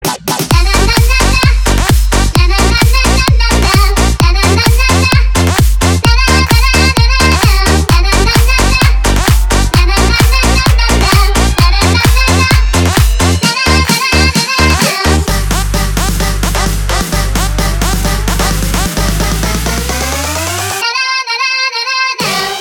• Качество: 320, Stereo
громкие
веселые
dance
без слов
club
progressive house
забавный голос
Позитивный прогрессив-хаус!